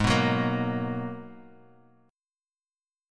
G#m9 chord